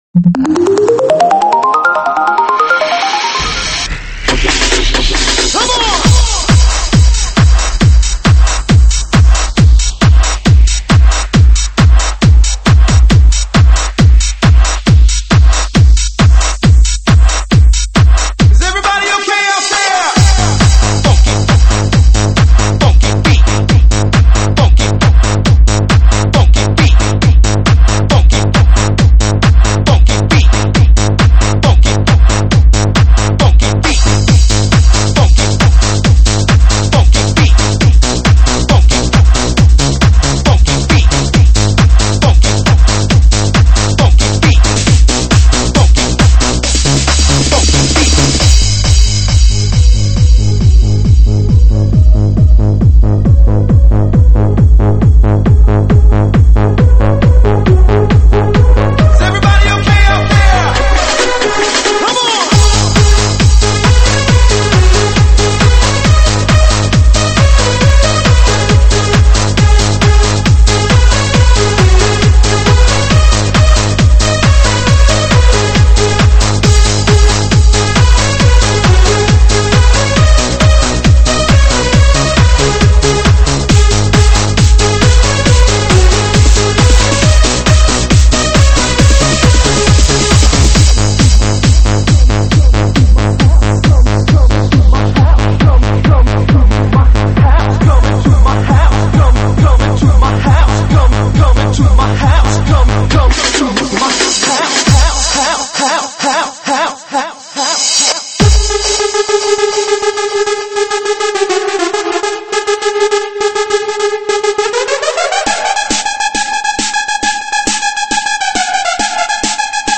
舞曲类别：独家发布